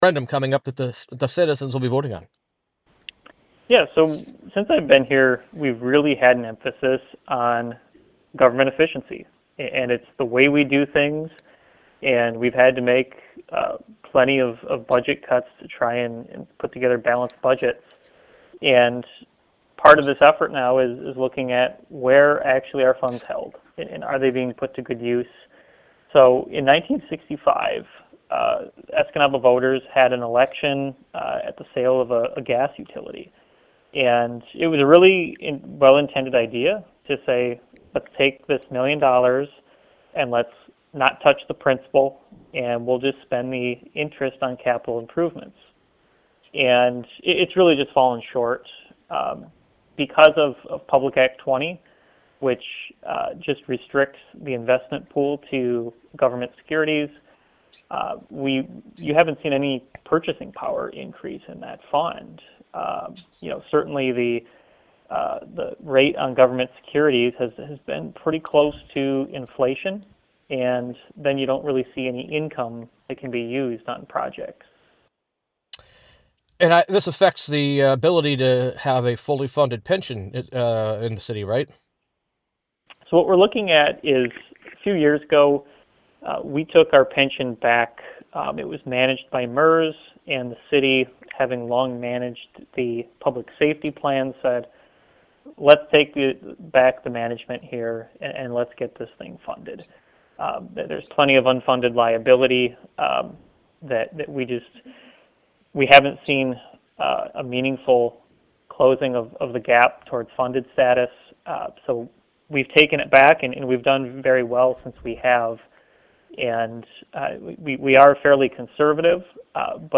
CLICK TO HEAR ESCANABA CITY MANAGER JIM MCNEIL INTERVIEW